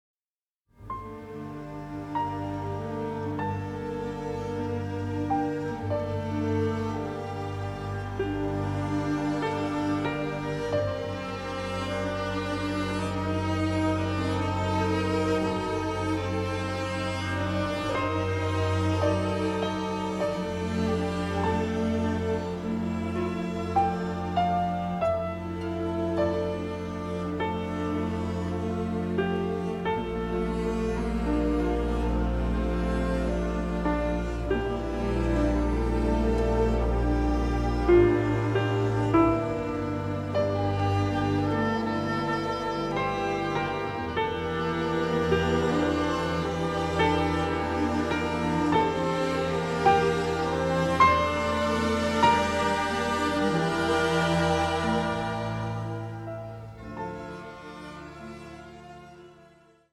deeply delicate score